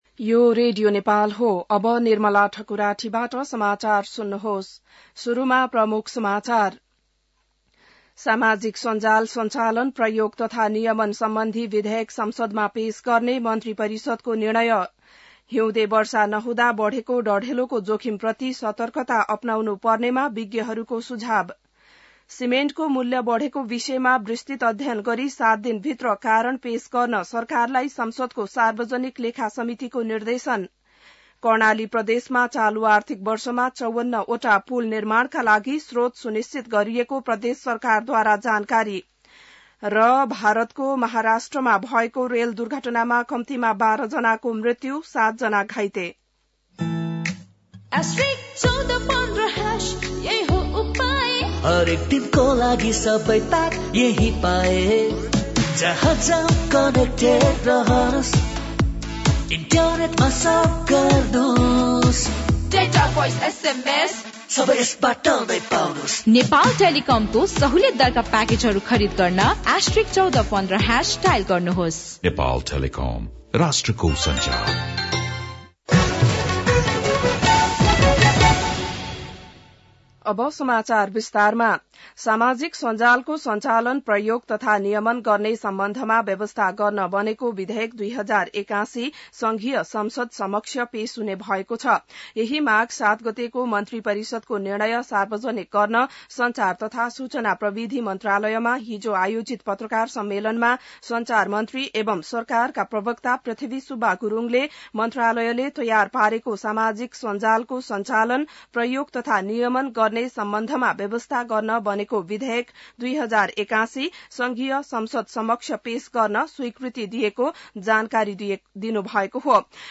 An online outlet of Nepal's national radio broadcaster
बिहान ७ बजेको नेपाली समाचार : ११ माघ , २०८१